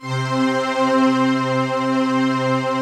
SI1 CHIME09R.wav